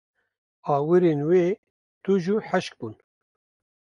Pronounced as (IPA)
/hɪʃk/